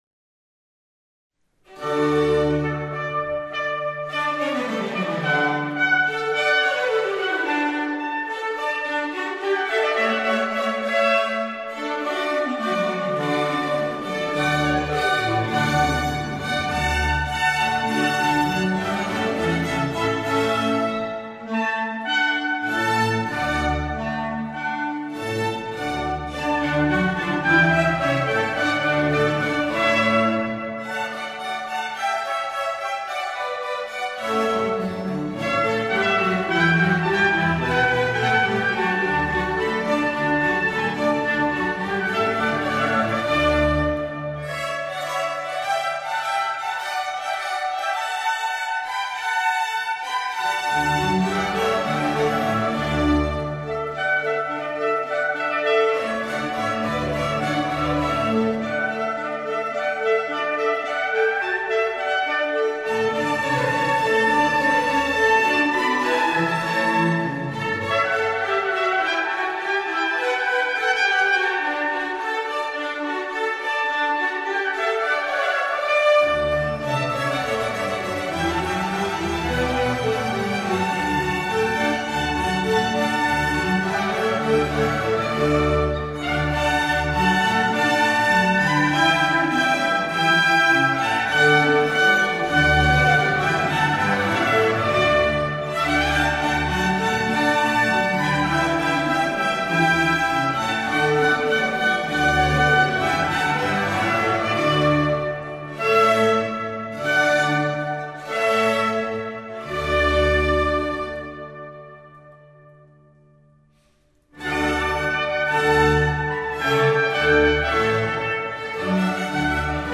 in der Entenfußhalle des Klosters Maulbronn
für Trompete u. Orchester D-Dur
Ouverture - Gigue - Aire - Bourrée - Marsch